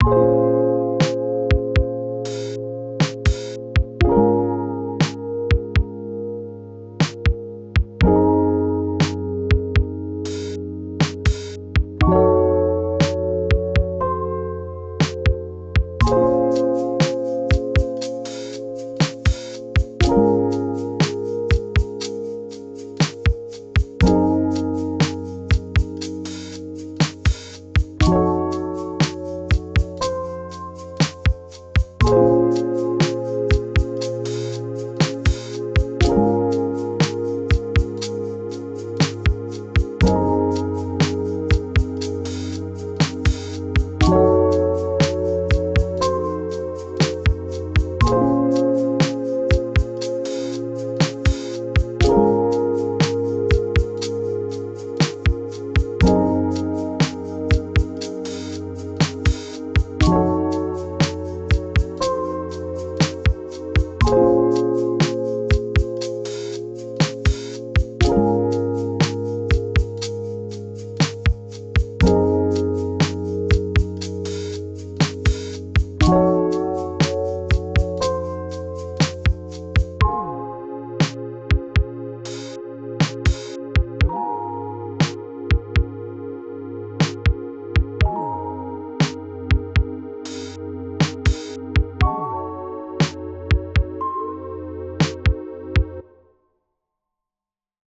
Lo-Fi Sunday Drive Intensity 2.wav